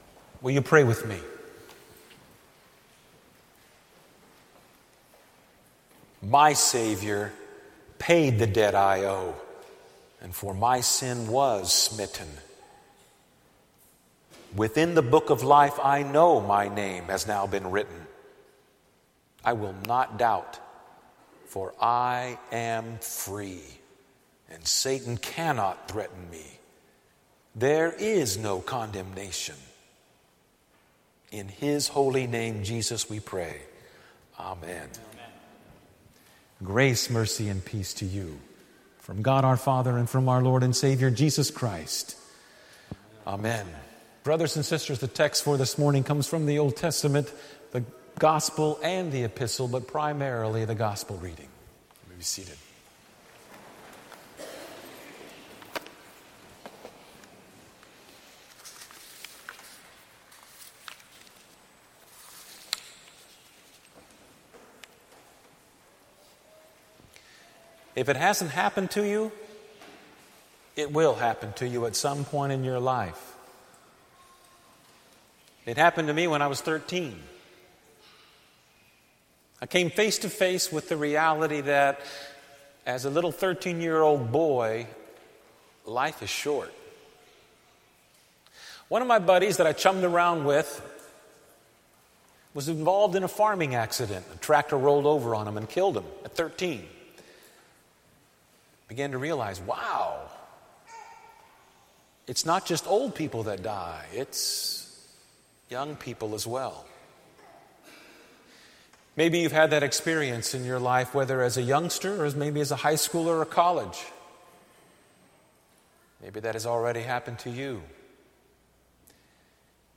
One of my buddies that I chummed around with, it’s a Lutheran Christian sermon.